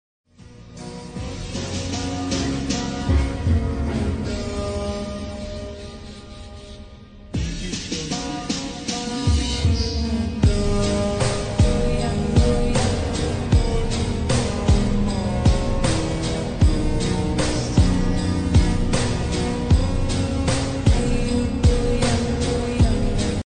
slowed